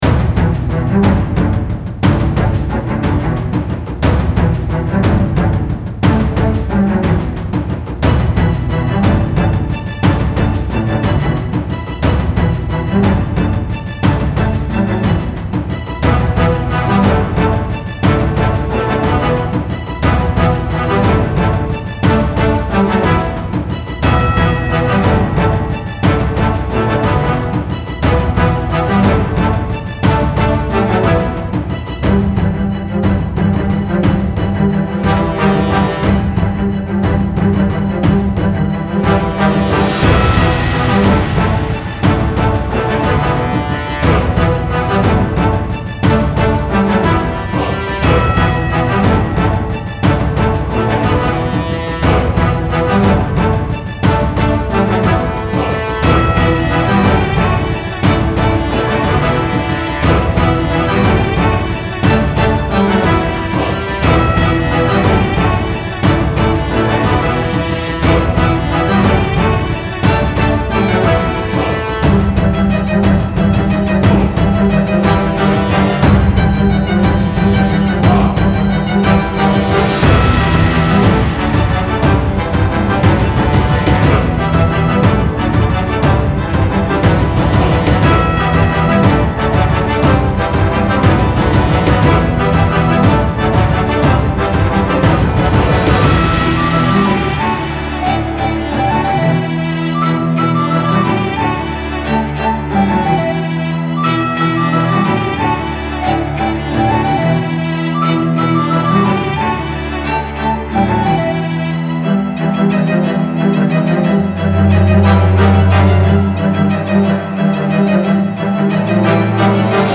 Style Style OrchestralSoundtrack
Mood Mood EpicIntense
Featured Featured BellsBrassChoirDrumsStringsWhistle
BPM BPM 120